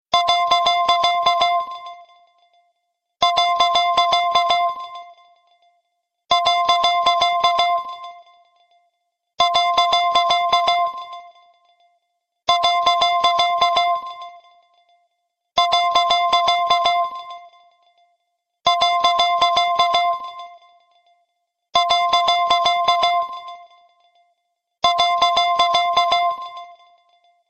simple-beep_25019.mp3